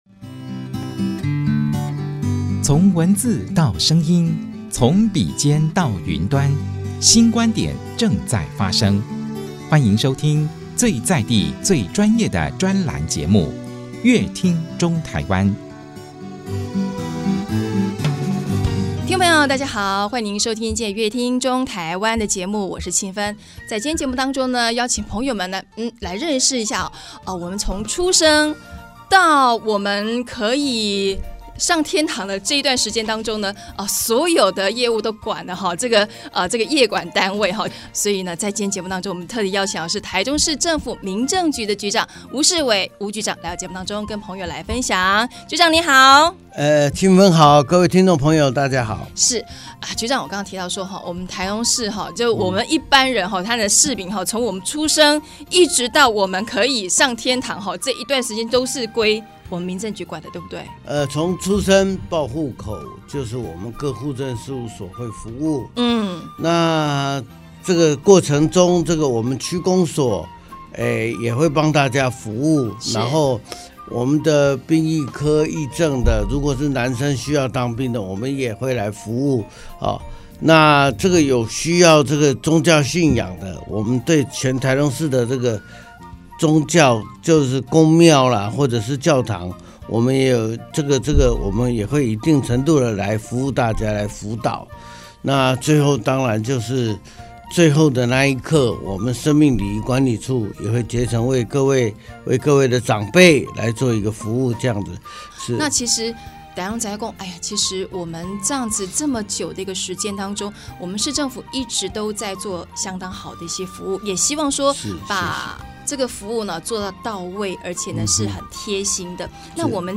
本集來賓：台中市政府民政局吳世瑋局長 本集主題：「『始』『終』在身邊的服務」 本集內容： 新生命出生的喜悅；人生走向終點的悲傷..，自始至終都能提供服務的單位是誰呢?答案是民政局，民政業務中有許多攸關民眾權益的服務，所以在今天節目中特地邀請台中市政府民政局吳世瑋局長來和大家分享『始』『終』在身邊的服務。